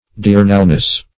Diurnalness \Di*ur"nal*ness\, n. The quality of being diurnal.